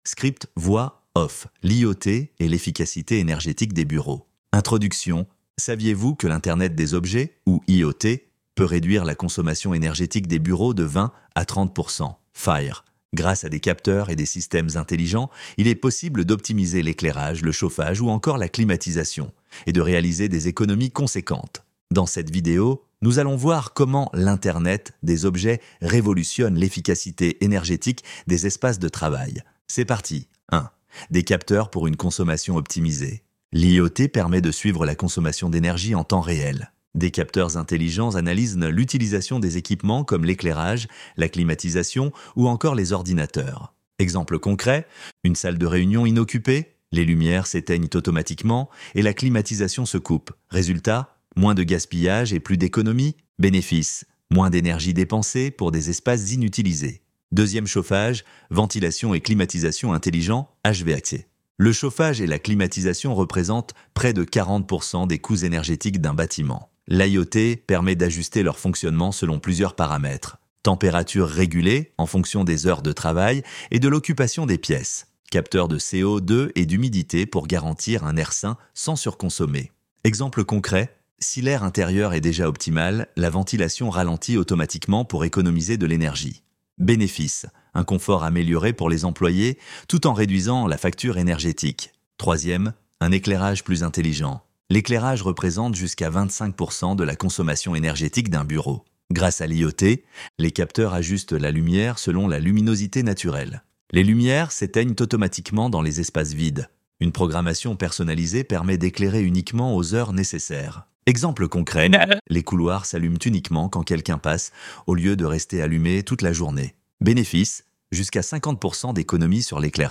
ElevenLabs_Chapter_1-28.mp3